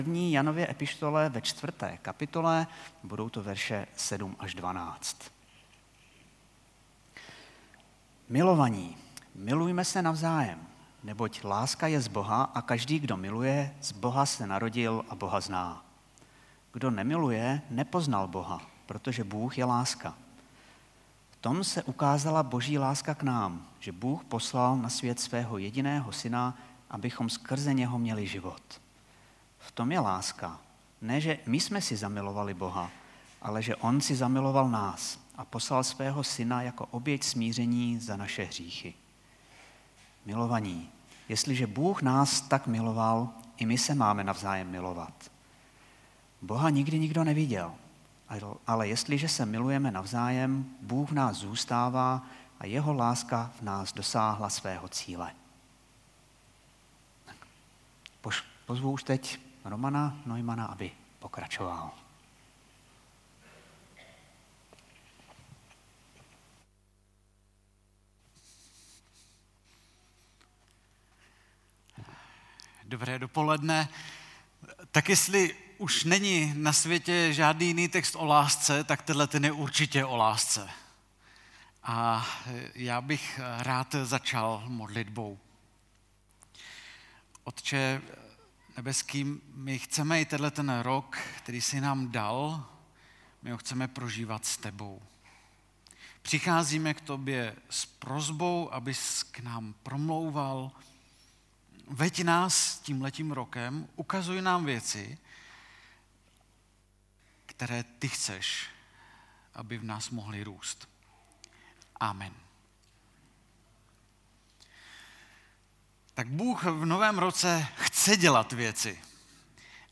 Novoroční bohoslužba